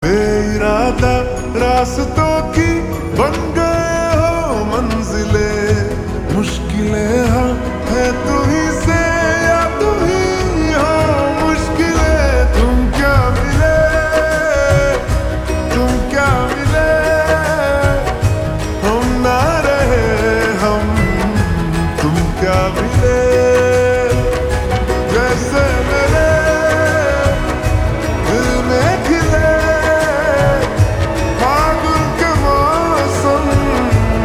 • Simple and Lofi sound
• Crisp and clear sound